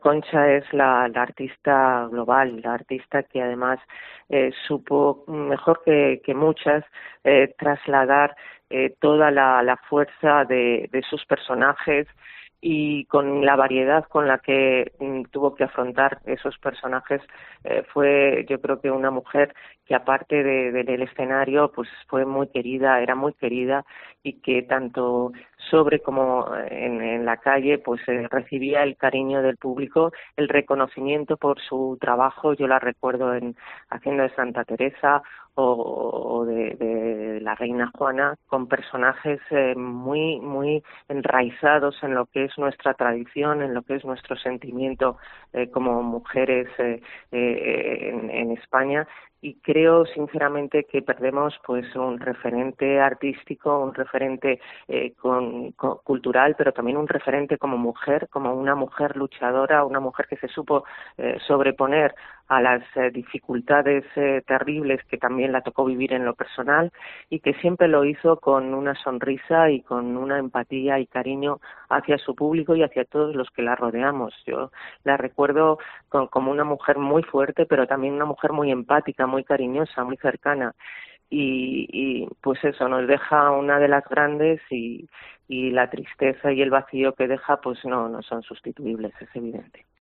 Ana Redondo, ministra del Gobierno de España, recuerda en COPE Valladolid a Concha Velasco